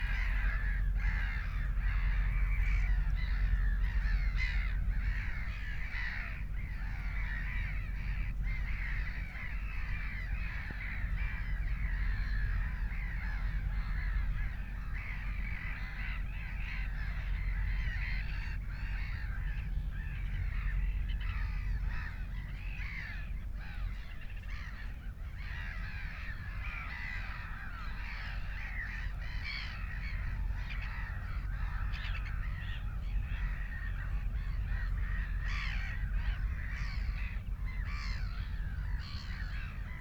smieszki.mp3